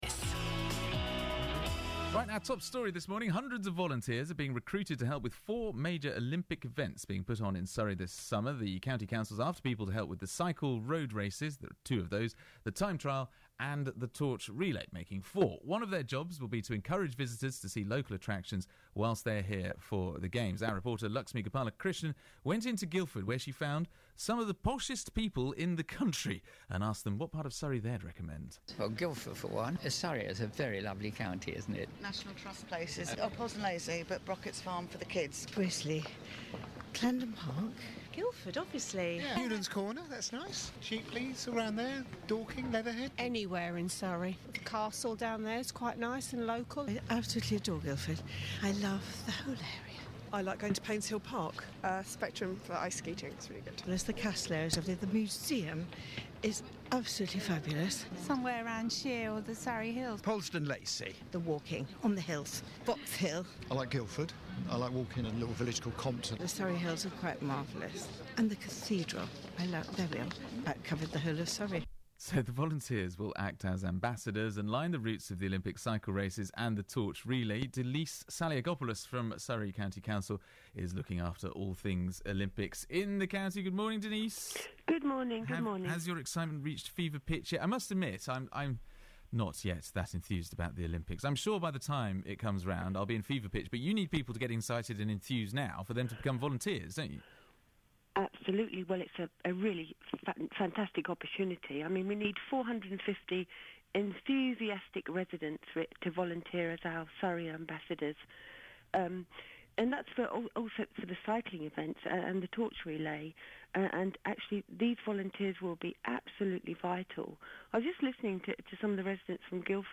Denise Saliagopoulos interviewed about Surrey Ambassadors
Denise Saliagopoulos, Surrey County Council’s Cabinet Member for Community Services and the 2012 Games, was interviewed on BBC Surrey about the council’s call for volunteers to act as Surrey Ambassadors when the Olympic cycle events and torch relay take place in the county.
– Audio courtesy of BBC Surrey